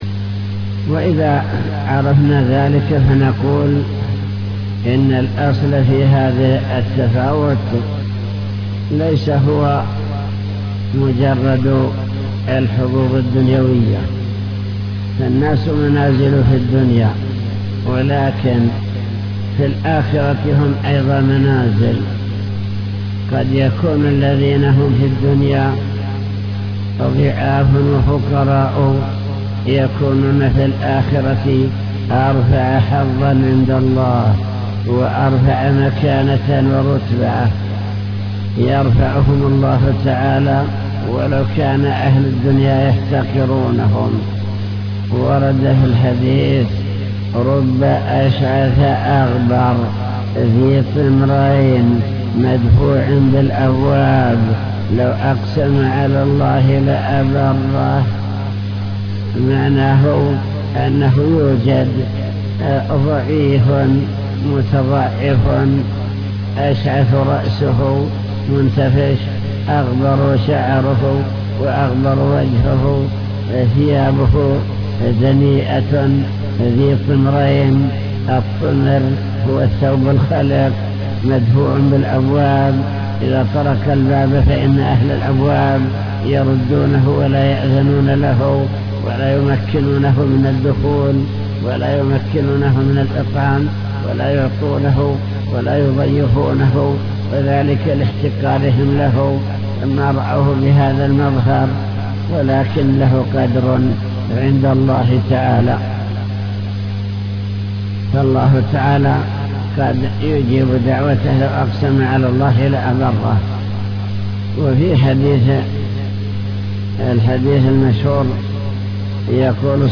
المكتبة الصوتية  تسجيلات - كتب  شرح كتاب بهجة قلوب الأبرار لابن السعدي شرح حديث اشفعوا فلتؤجروا